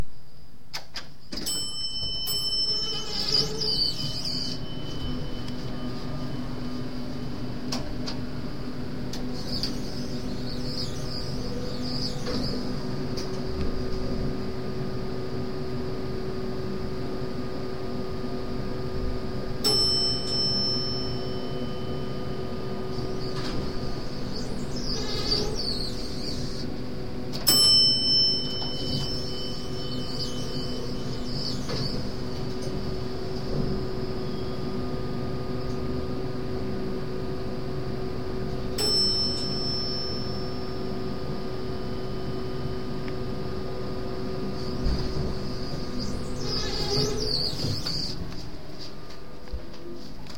Field Recording #12
SOUND CLIP: Republic Hall Elevator LOCATION: Republic Hall elevator SOUNDS HEARD: button pushed, bell ringing, door shutting, exhaust fan, elevator moving/stopping